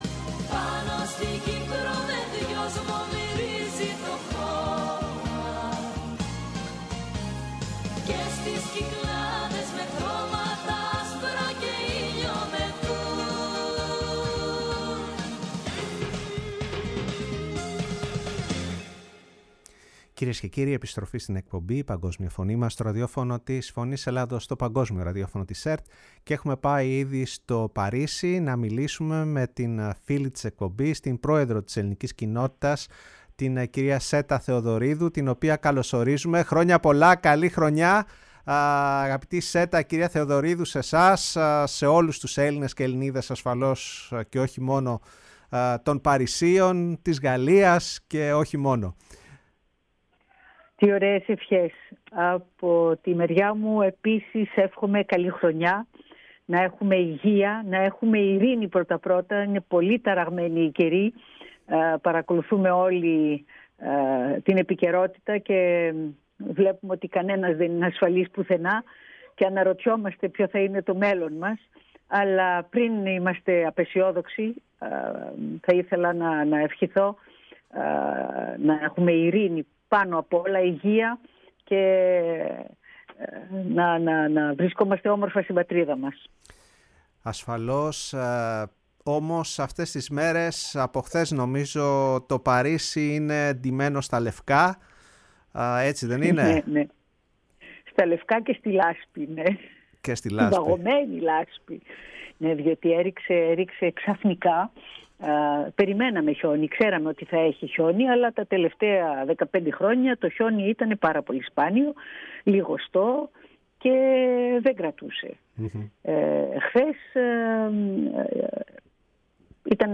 μιλώντας στο Ραδιόφωνο της Φωνής της Ελλάδας και στην εκπομπή “Η Παγκόσμια Φωνή μας”